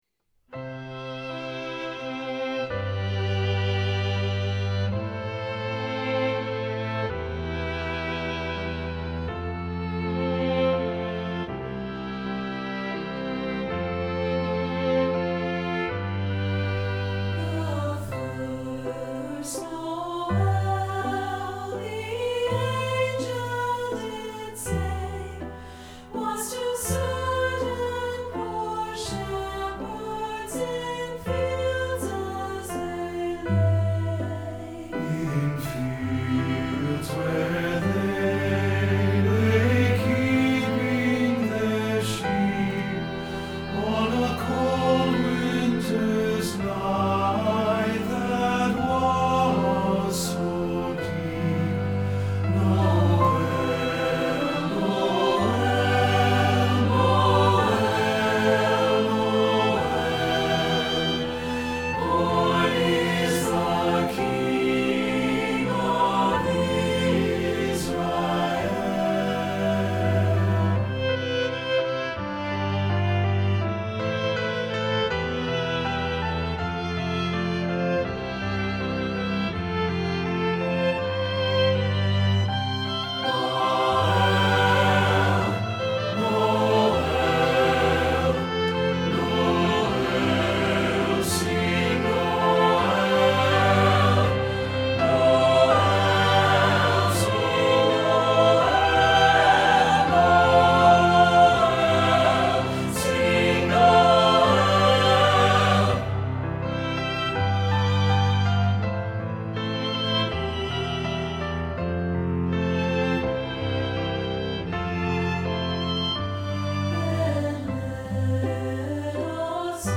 Choral Christmas/Hanukkah
SATB